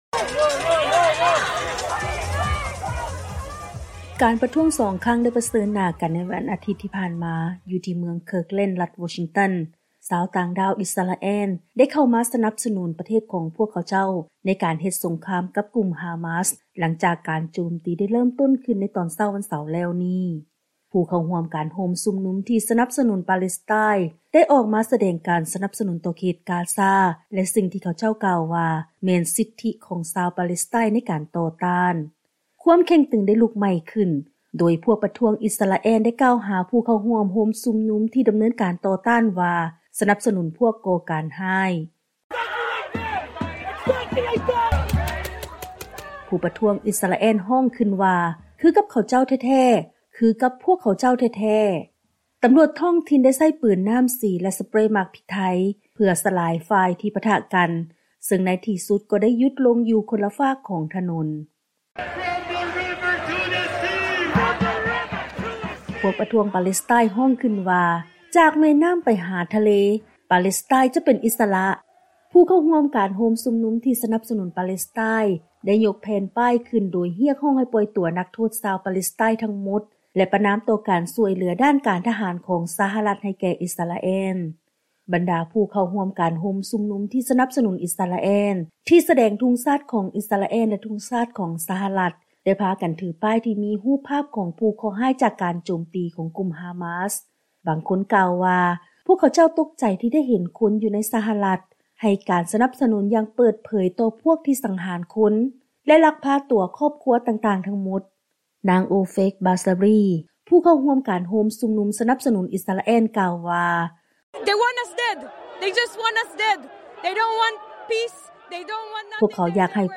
ເຊີນຟັງລາຍງານກ່ຽວກັບ ການປະທະກັນລະຫວ່າງ ຊາວຕ່າງດ້າວປາແລັສໄຕນ໌ ແລະຊາວຕ່າງດ້າວອິສຣາແອລ ໃນລັດວໍຊິງຕັນ